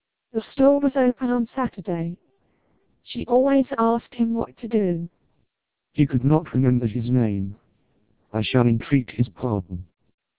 Speech Samples (WAV-files).
British